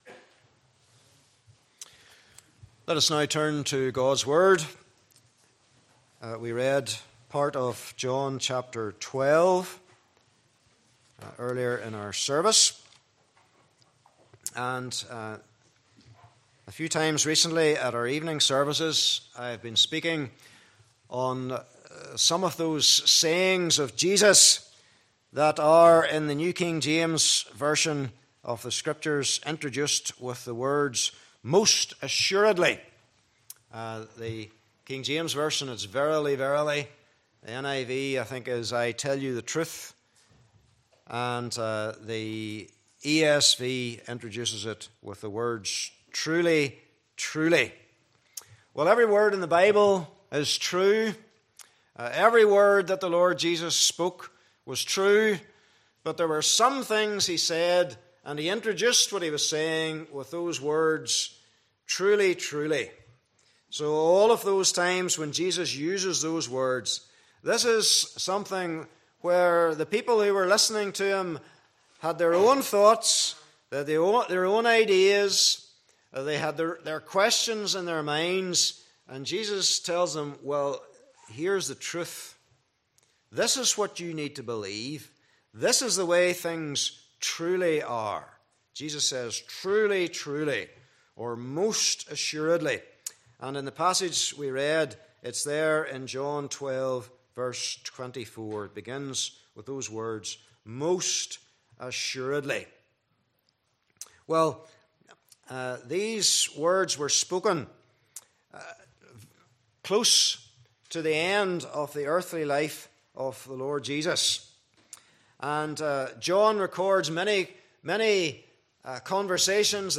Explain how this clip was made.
Passage: John 12 : 20 -27 Service Type: Morning Service Bible Text